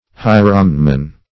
Hieromnemon \Hi`e*rom*ne"mon\, n. [NL., from Gr.